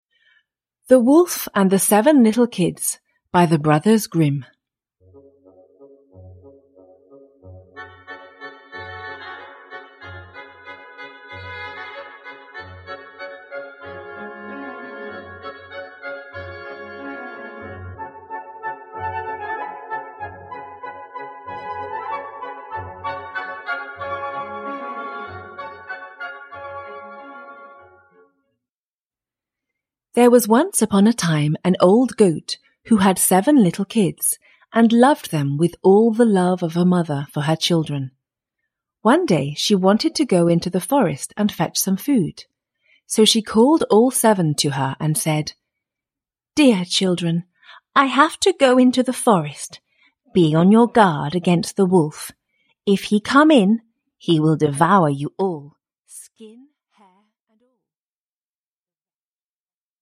The Wolf and the Seven Little Kids, a Fairy Tale – Ljudbok – Laddas ner